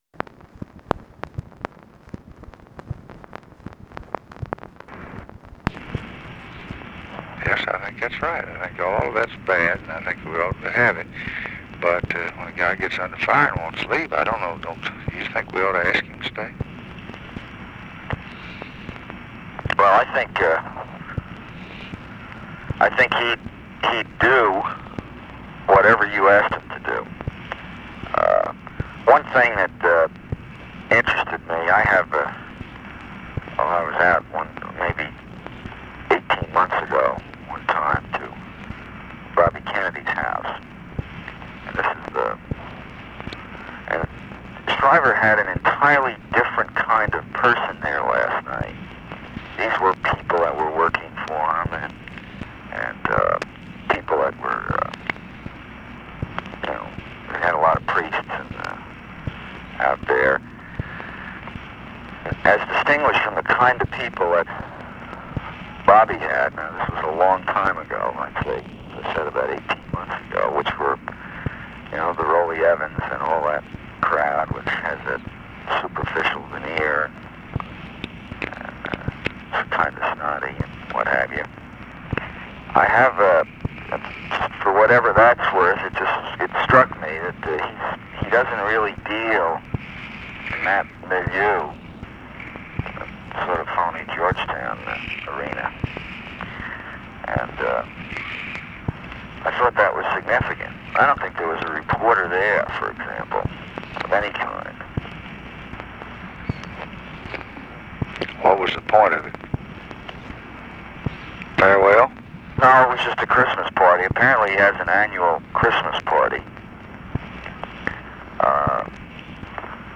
Conversation with JOSEPH CALIFANO, December 21, 1966
Secret White House Tapes